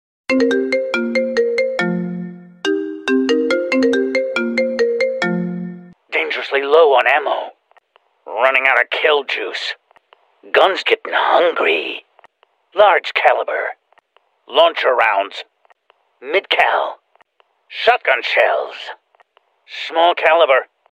This creepy call might just give you chills!
Disclaimer: This is a fun fake call video and not affiliated with any official character or franchise.